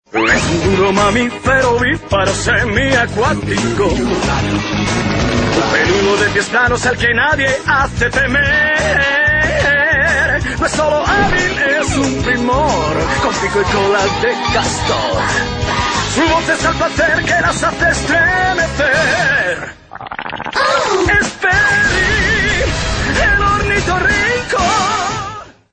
Esta es la melodía de introducción.